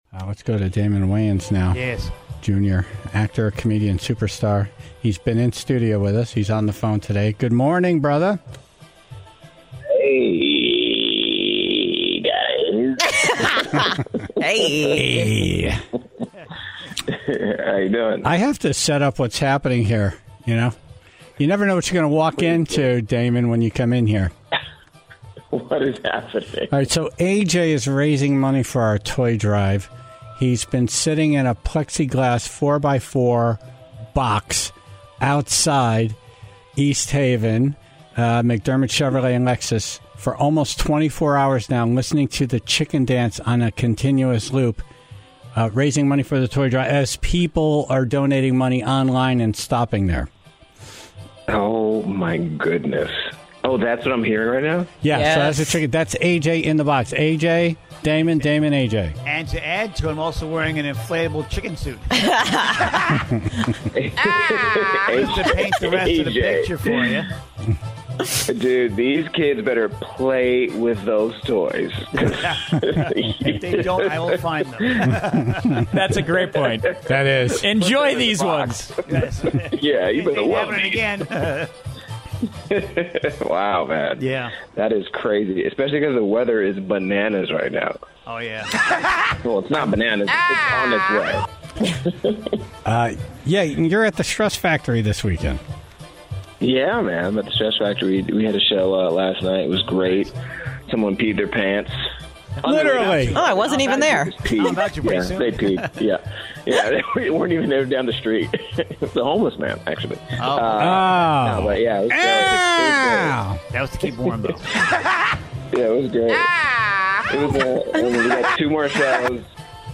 He called in to promote his show at the Stress Factory in Bridgeport